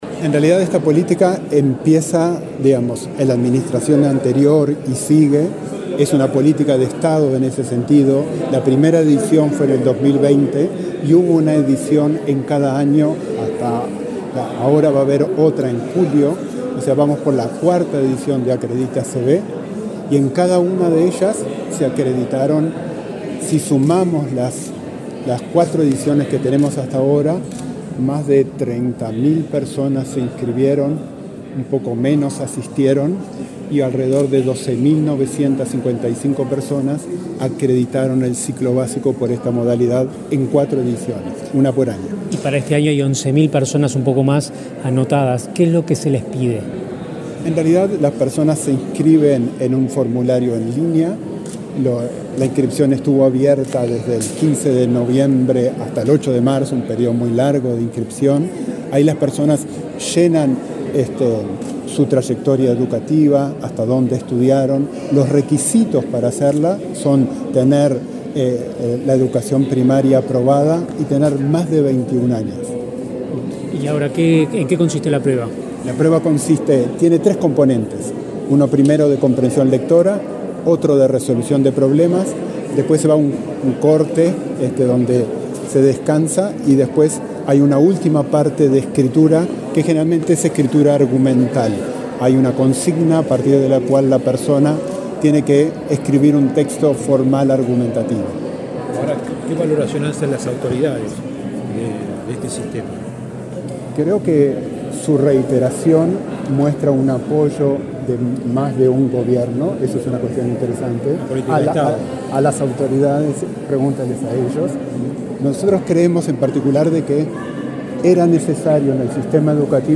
Declaraciones a la prensa del director de Investigación, Evaluación y Estadística de ANEP Andrés Peri
Luego dialogó con la prensa.